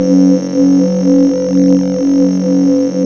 SYN TECHNO08.wav